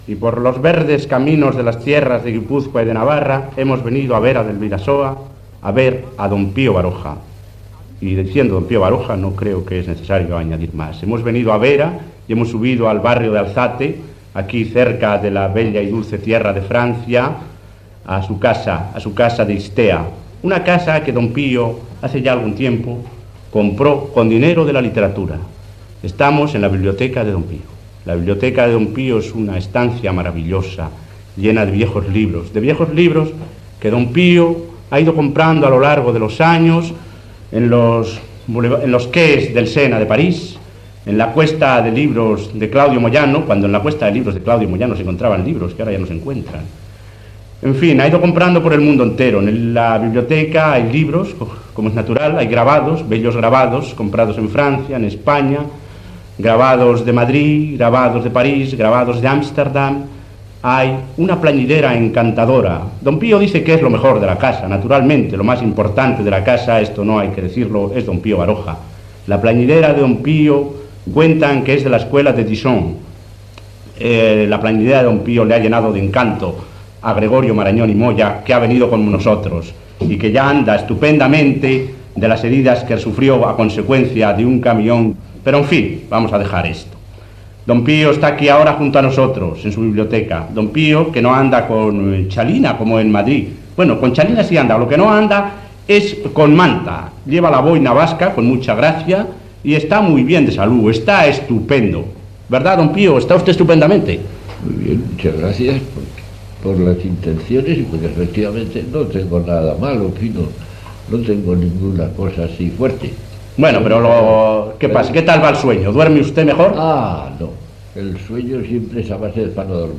a7d36e834ce35c115d926222af37fb3a19c436a6.mp3 Títol Cadena SER Emissora Ràdio Barcelona Cadena SER Titularitat Privada estatal Descripció Entrevista a l'escriptor Pío Baroja feta a la biblioteca de la seva casa d'Itzea, a Vera de Bidasoa (Navarra). S'hi parla del seu estat de salut, de les minyones, de com passa els dies, de les seves lectures, del cinema, de la seva feina com a metge, de la possibilitat de fer un viatge a la LLuna, del que està escrivint